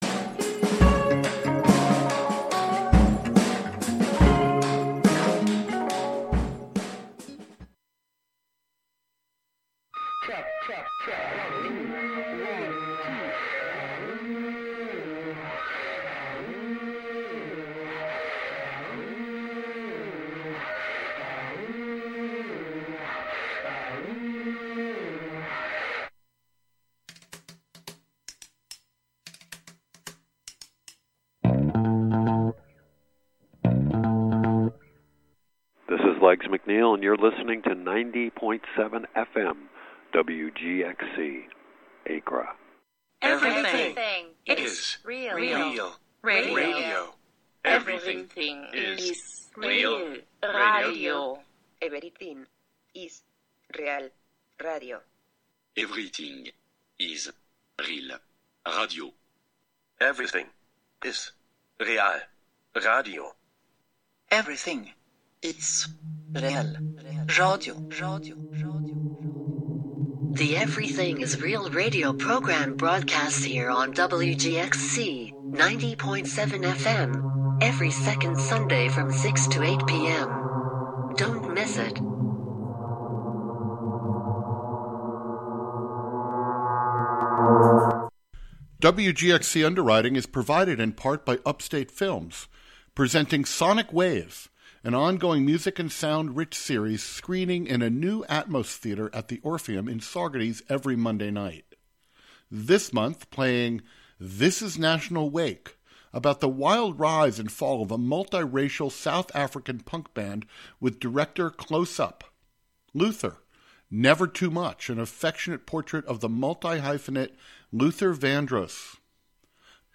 An upbeat music show featuring the American songbook as interpreted by contemporary artists as well as the jazz greats in a diverse range of genres live from WGXC's Catskill studio.